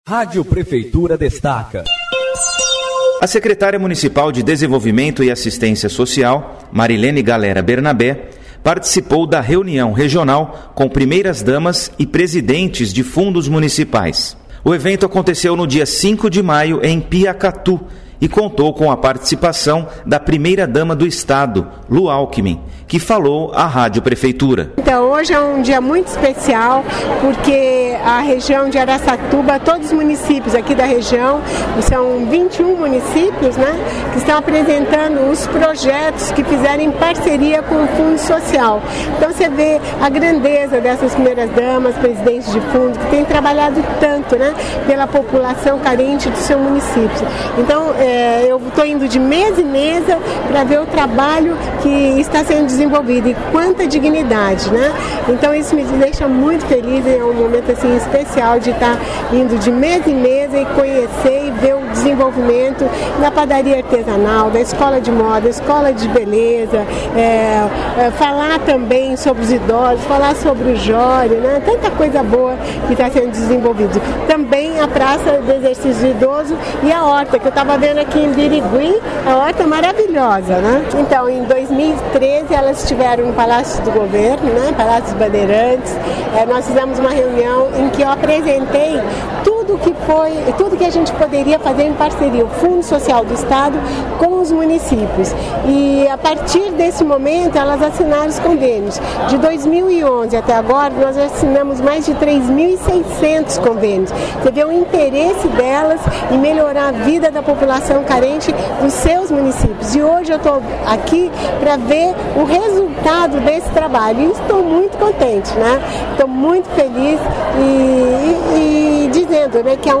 A Rádio Prefeitura entrevistou a primeira-dama do Estado, Lu Alckim, que falou sobre o Jori.
lu_alckmin_em_piacatu.mp3